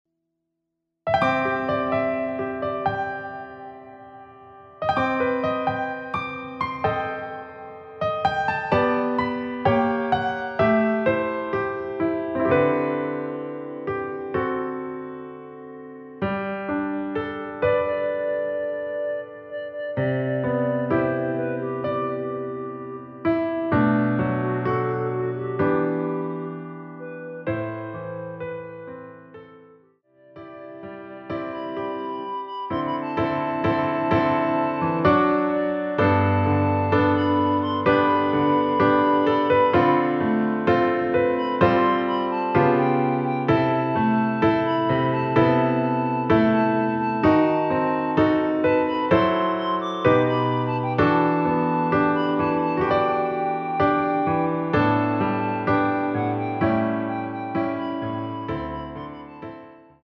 여성분이 부르실수 있는 멜로디 포함된 MR 입니다.(미리듣기 참조)
앞부분30초, 뒷부분30초씩 편집해서 올려 드리고 있습니다.
중간에 음이 끈어지고 다시 나오는 이유는